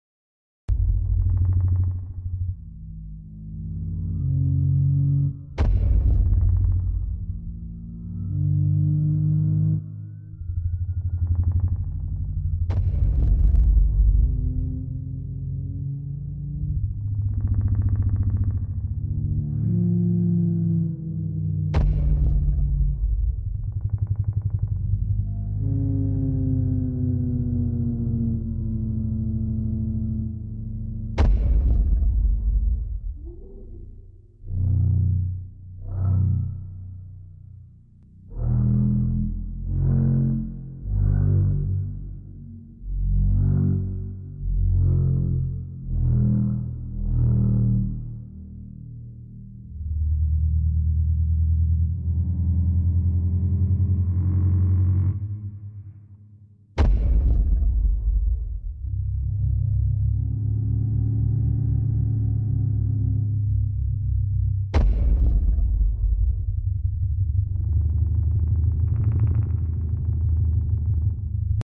Sound of a real trex!!! sound effects free download